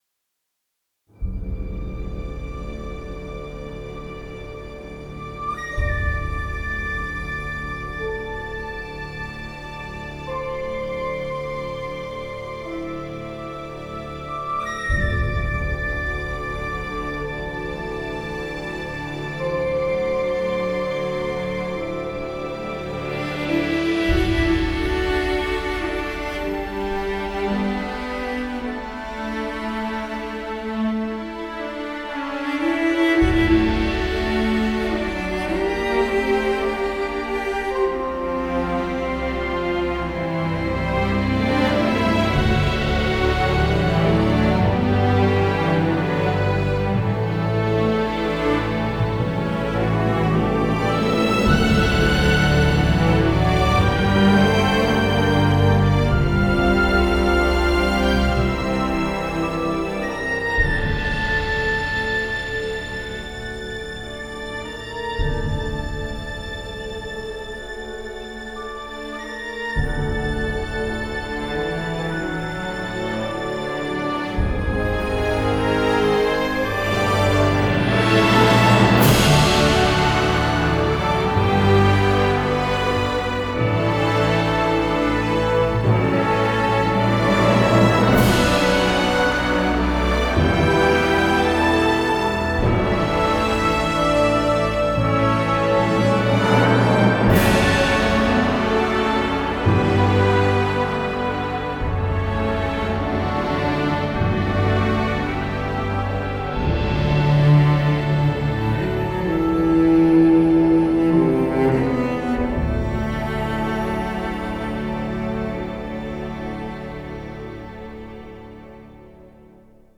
A bolder rendition